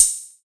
• Crash Sample C# Key 05.wav
Royality free cymbal crash sound tuned to the C# note.
crash-sample-c-sharp-key-05-sUt.wav